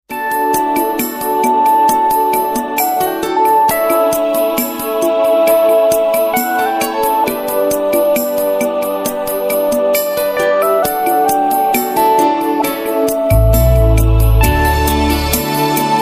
акустическая гитара
электрогитара
бас-гитара